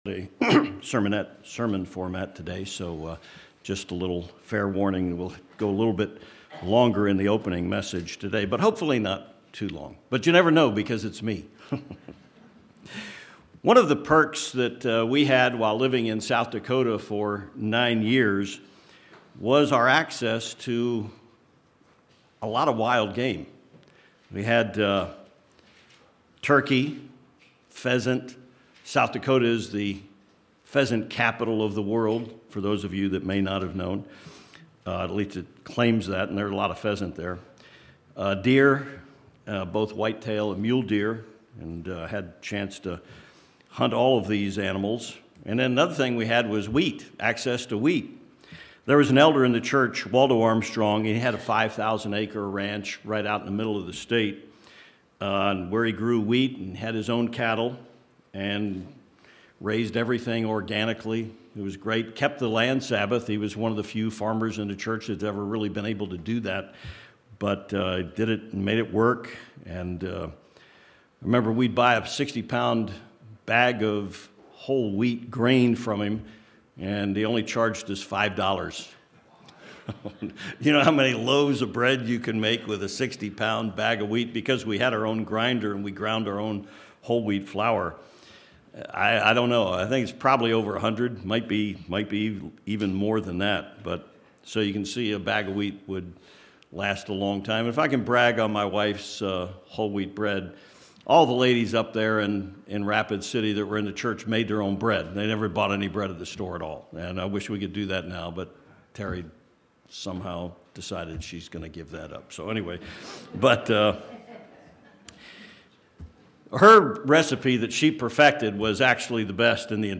This Split Sermon examines Exodus 16, and offers further proof of the Passover observance at the beginning of the 14th of Nisan, as well as showing God instructing Israel when to keep the Sabbath day holy.